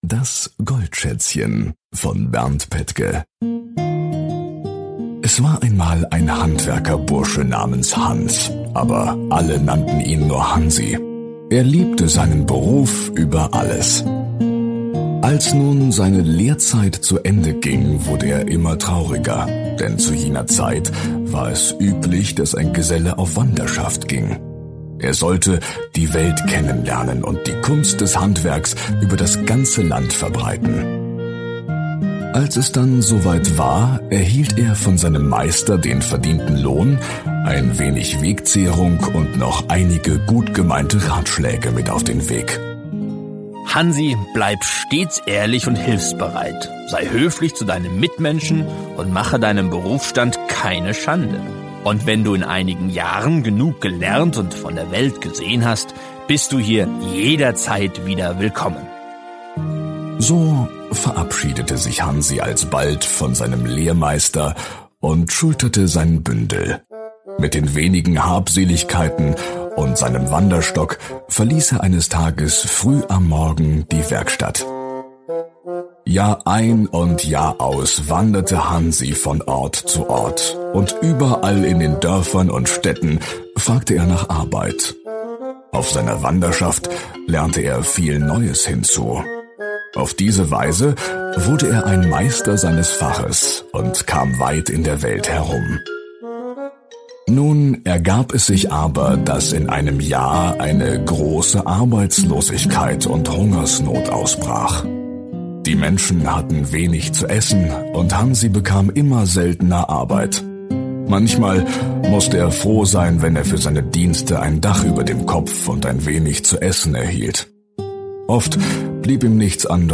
Ein Radiomärchen